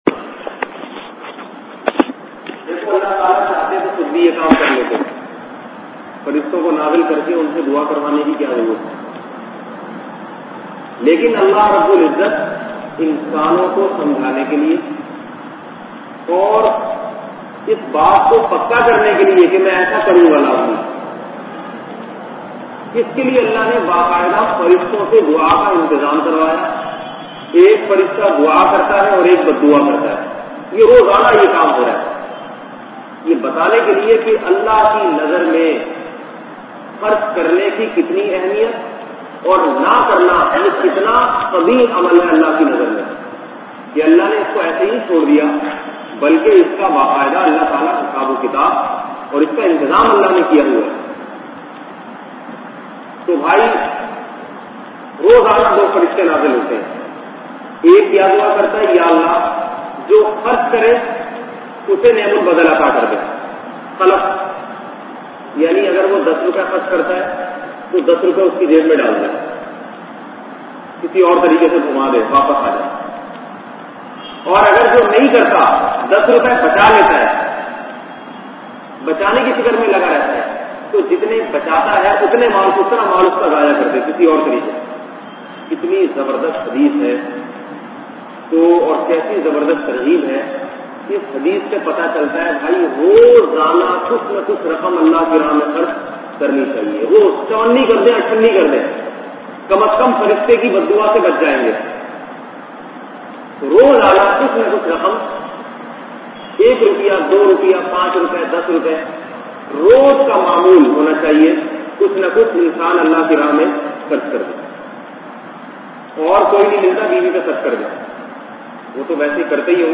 Maal Zaya Honay Par Rona bayan mp3